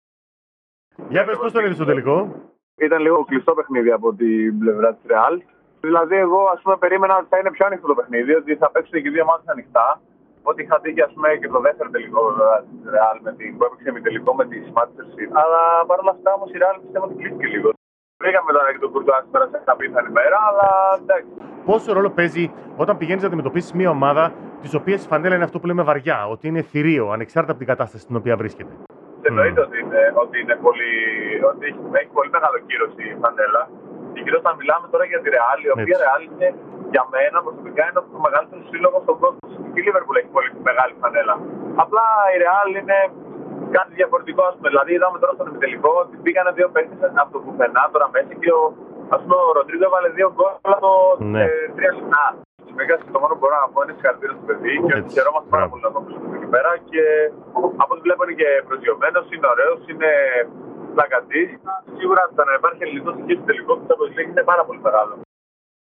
Τη δική τους οπτική για τον τελικό του Τσάμπιονς Λιγκ, έδωσαν τρεις αθλητές άλλων αθλημάτων στην ΕΡΑ ΣΠΟΡ.